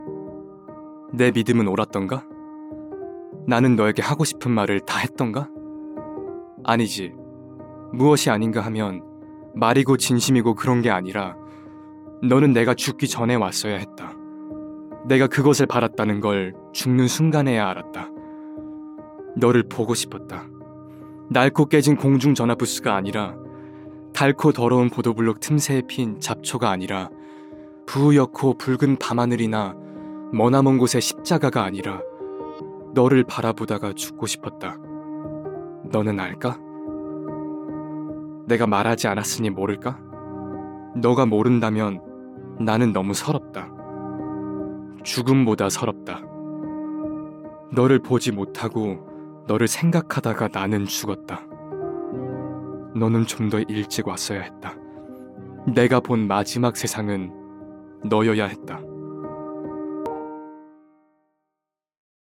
Korean, Male, 20s-30s